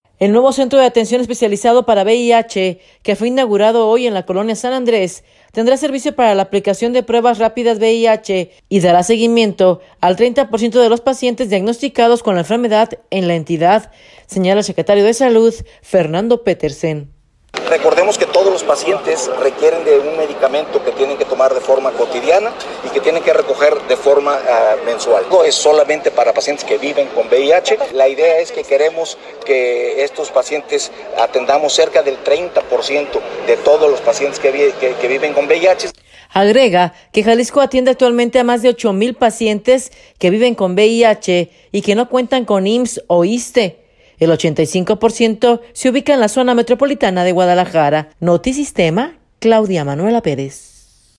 audio El nuevo Centro de Atención Especializado para VIH, que fue inauguró hoy en la colonia San Andrés, tendrá servicio para la aplicación de pruebas rápidas VIH y dará seguimiento al 30 por ciento de los pacientes diagnosticados con la enfermedad en la entidad, señala el secretario de Salud, Fernando Petersen.